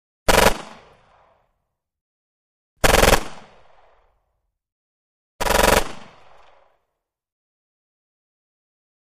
9 mm Mac-10 Automatic: Multiple Bursts; Three Loud Bursts Of Very Rapid Fire Shots With Interior Sounding, Long Echo. Close Up Perspective. Gunshots.